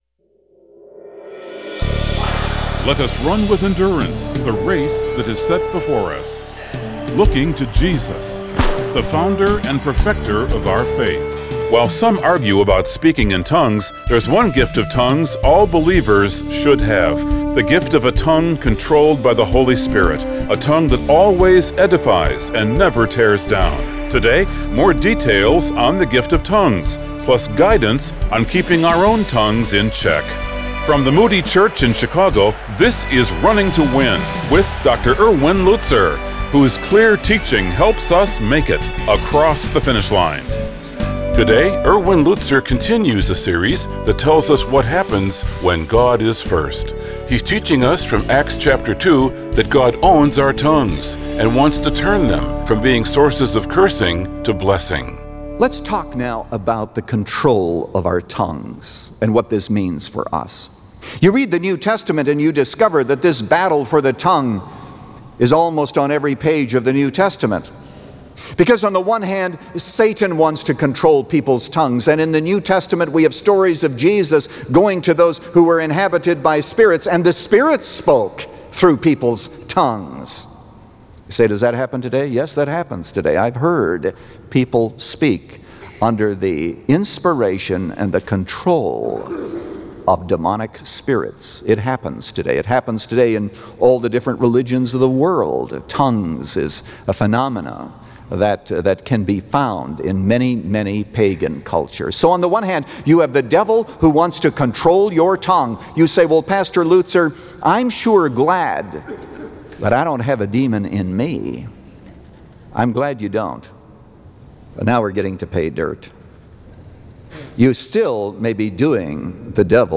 Today this program broadcasts internationally in six languages.